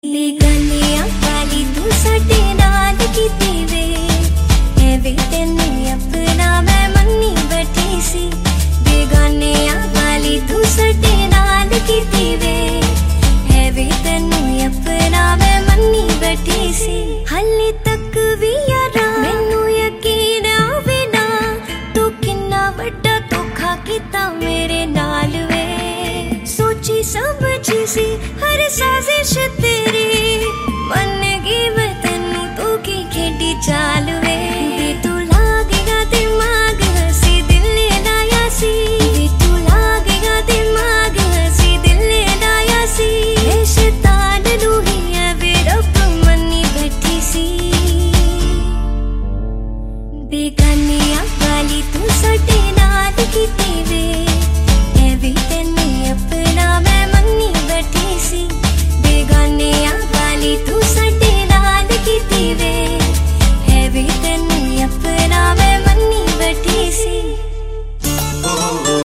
Ringtones Category: Bollywood